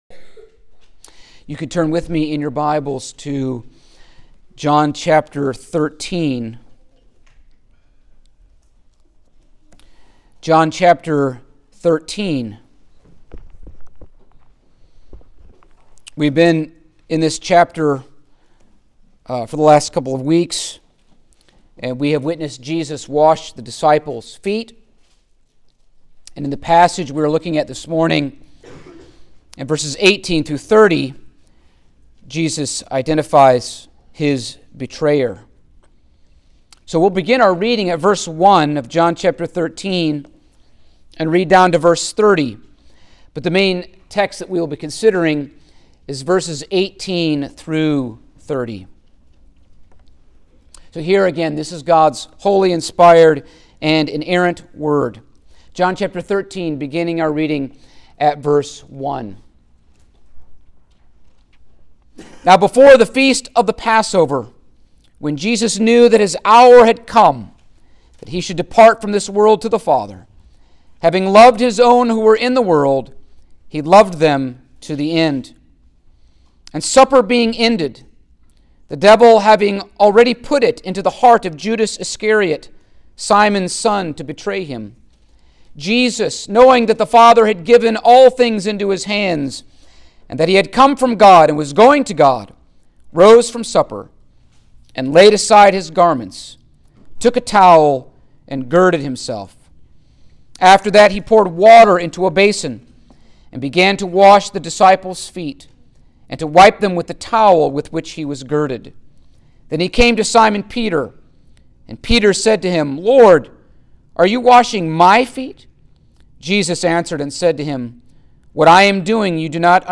The Gospel of John Passage: John 13:18-30 Service Type: Sunday Morning Topics